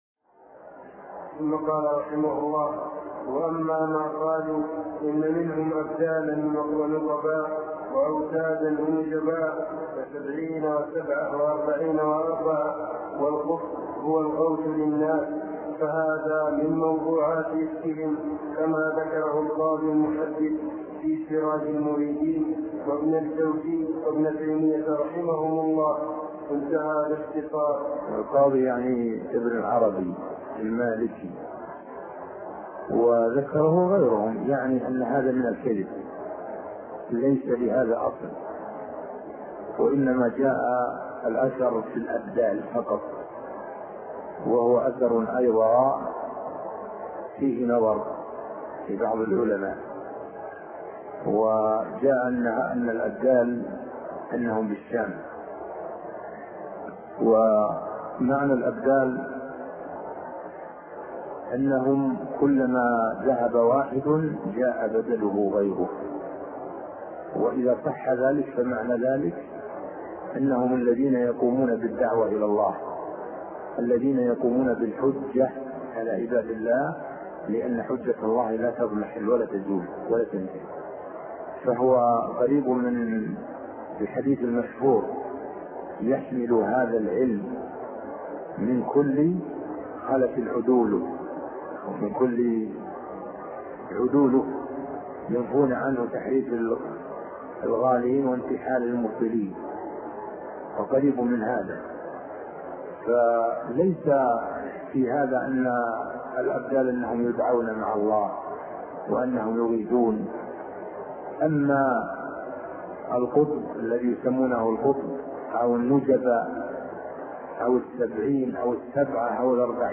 عنوان المادة الدرس ( 47) شرح فتح المجيد شرح كتاب التوحيد تاريخ التحميل الجمعة 16 ديسمبر 2022 مـ حجم المادة 23.05 ميجا بايت عدد الزيارات 209 زيارة عدد مرات الحفظ 126 مرة إستماع المادة حفظ المادة اضف تعليقك أرسل لصديق